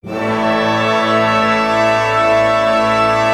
Index of /90_sSampleCDs/Roland - String Master Series/ORC_ChordCluster/ORC_Pentatonic